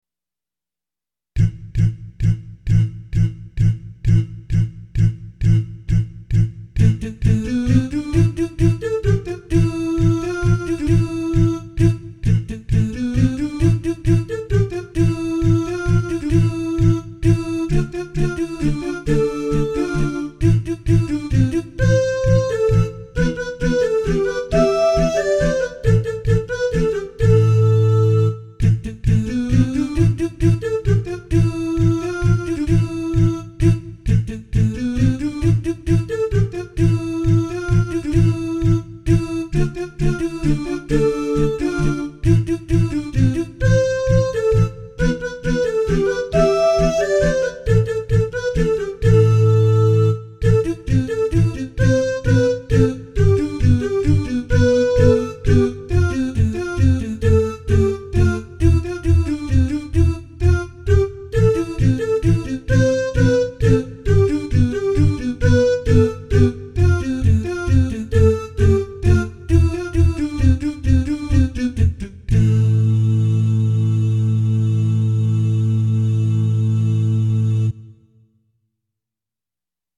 3. Vocal Ensemble